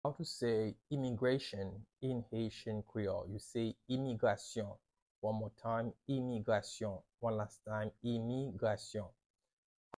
“Immigration” in Haitian Creole – “Imigrasyon” pronunciation by a Haitian Creole teacher
“Imigrasyon” Pronunciation in Haitian Creole by a native Haitian can be heard in the audio here or in the video below:
How-to-say-Immigration-in-Haitian-Creole-–-Imigrasyon-pronunciation-by-a-Haitian-Creole-teacher.mp3